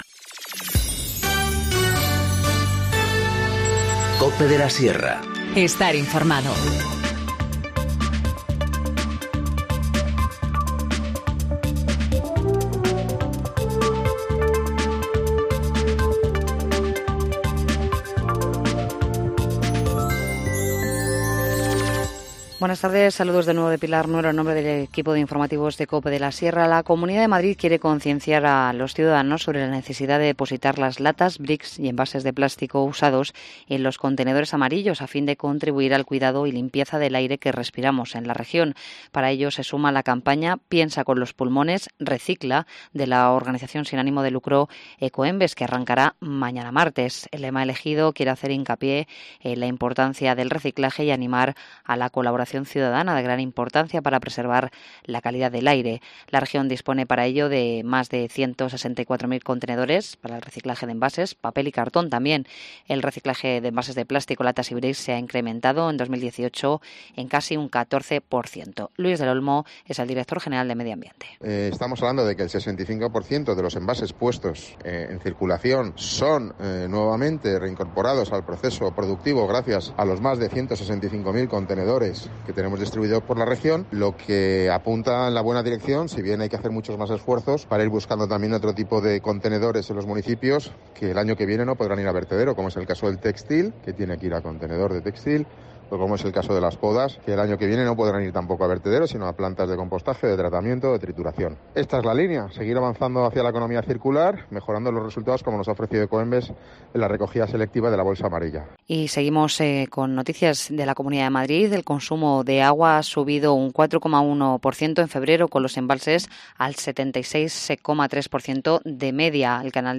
Informativo Mediodía 4 marzo-14:50h